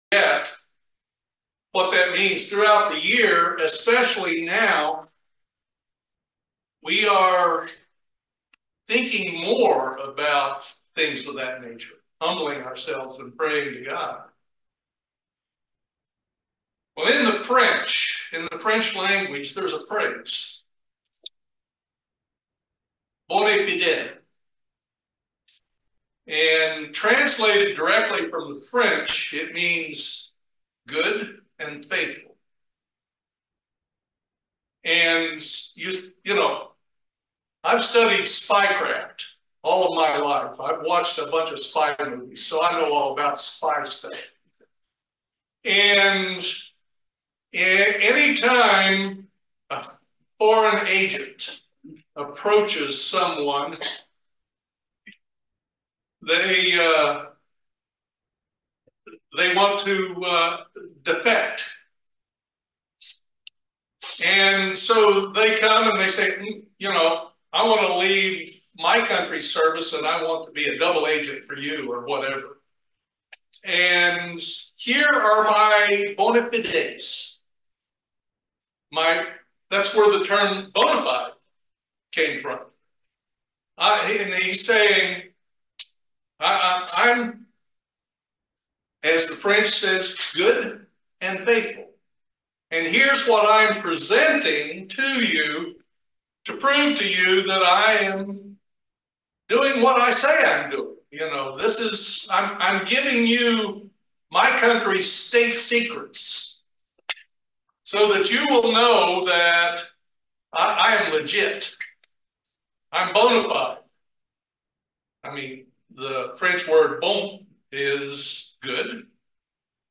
Join us for this excellent video sermon.
Given in Lexington, KY